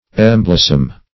Search Result for " emblossom" : The Collaborative International Dictionary of English v.0.48: Emblossom \Em*blos"som\, v. t. To cover or adorn with blossoms.